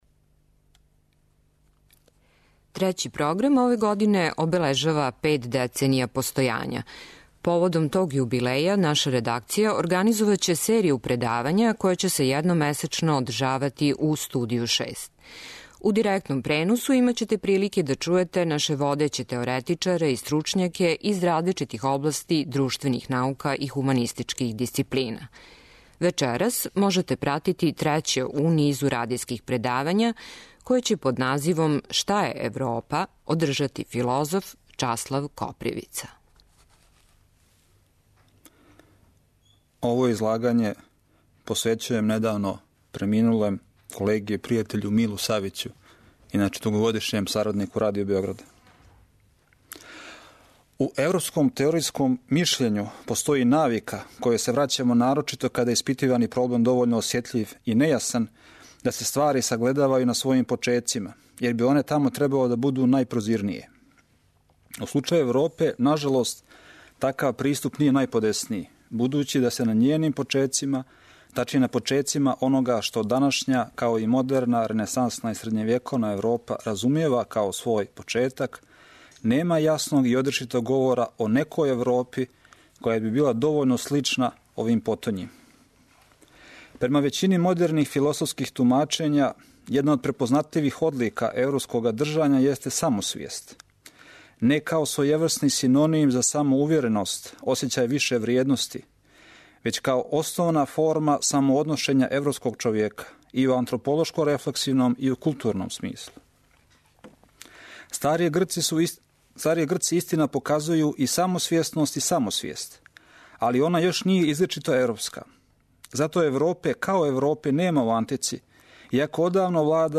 Радијско предавање у Студију 6